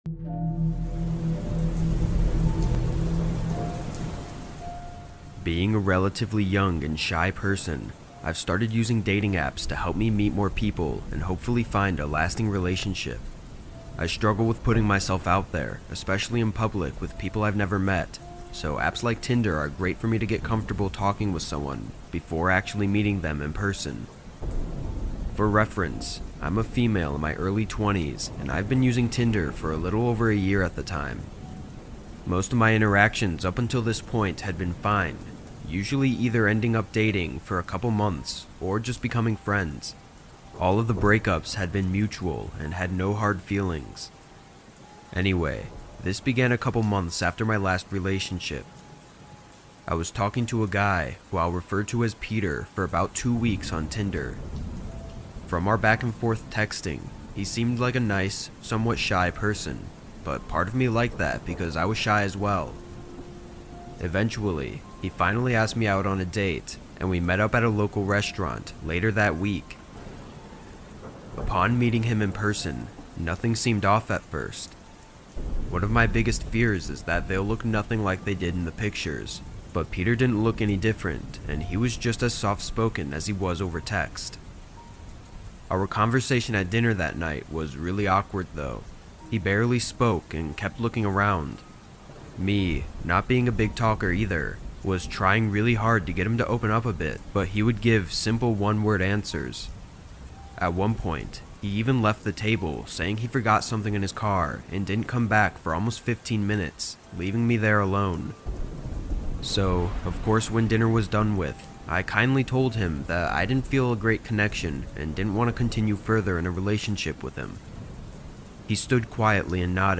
Disturbing True Stalker Horror Stories | With Rain Sounds That Will Make You Look Over Your Shoulder